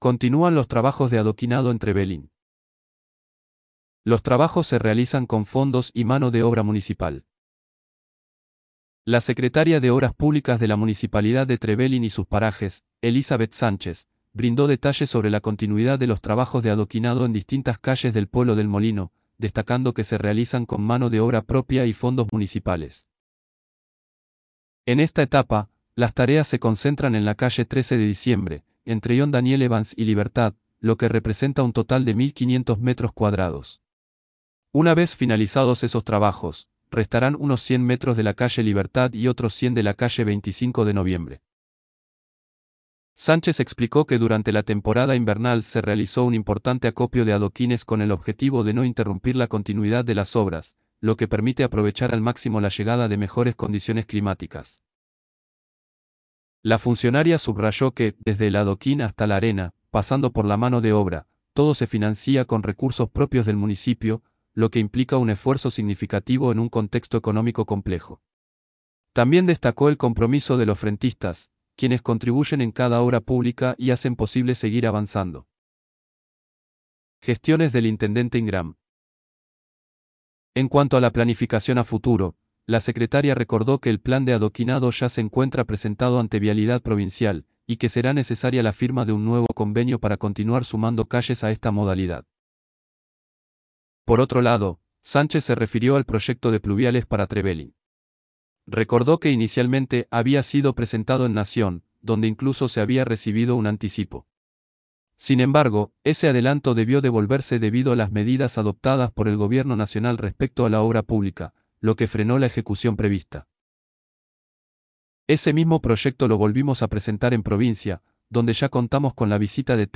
Los trabajos se realizan con fondos y mano de obra municipal La secretaria de Obras Públicas de la Municipalidad de Trevelin y sus Parajes, Elizabeth Sánchez, brindó detalles sobre la continuidad de los trabajos de adoquinado en distintas calles del Pueblo del Molino, destacando que se realizan con mano de obra propia y fondos municipales. En esta etapa, las tareas se concentran en la calle 13 de Diciembre, entre John Daniel Evans y Libertad, lo que representa un total de 1.500 metros cuadrados.